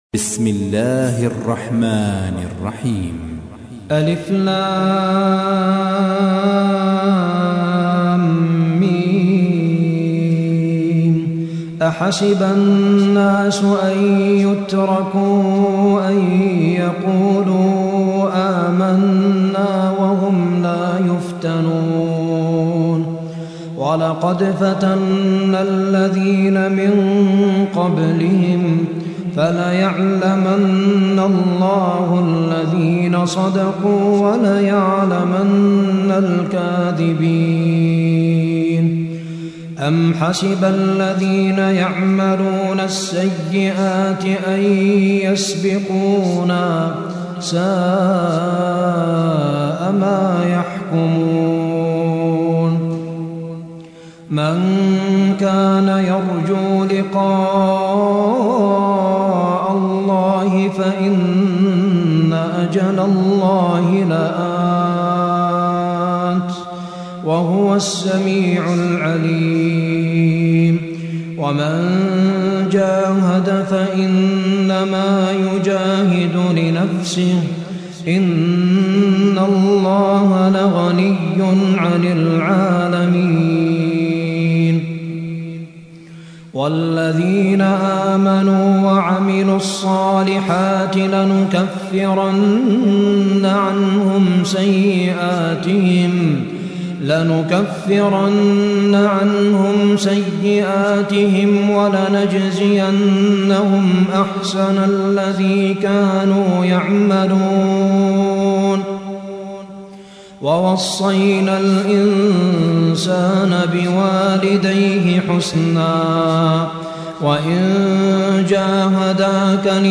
29. سورة العنكبوت / القارئ